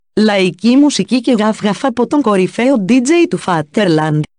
laiki-female.mp3